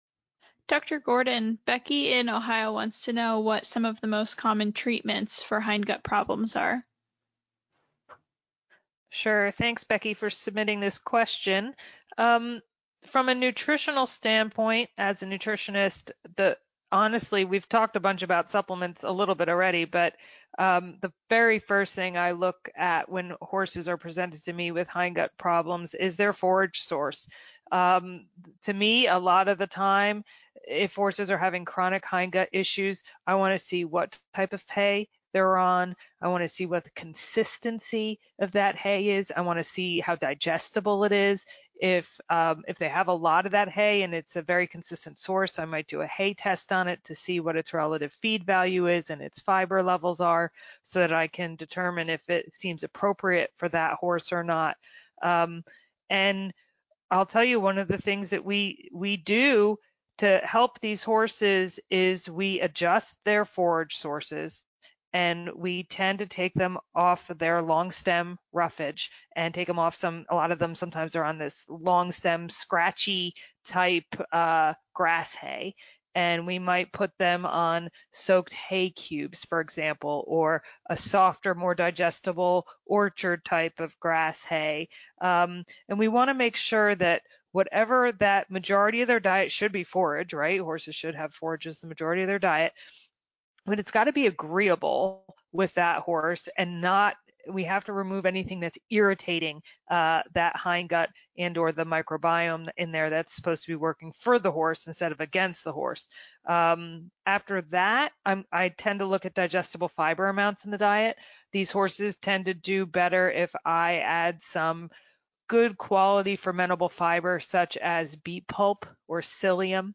This podcast is an excerpt of our Ask TheHorse Live Q&A, "Understanding Equine Hindgut Health."